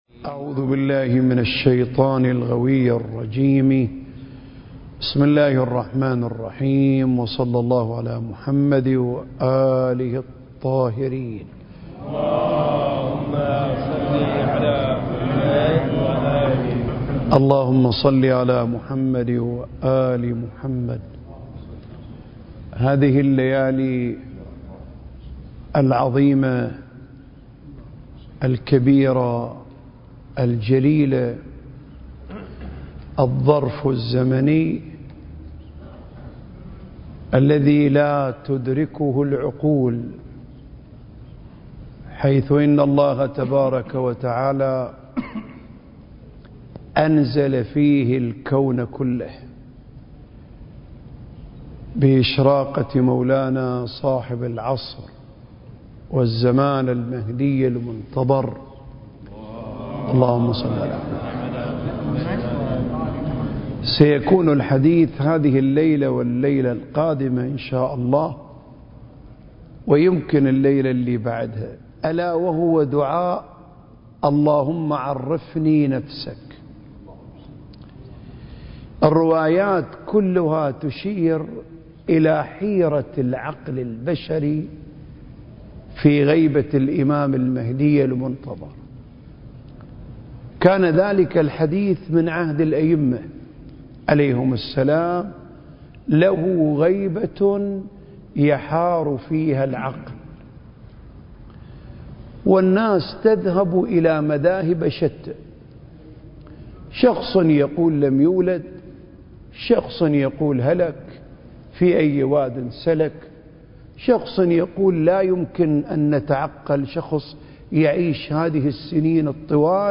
سلسلة محاضرات: آفاق المعرفة المهدوية (1) المكان: الأوقاف الجعفرية بالشارقة التاريخ: 2023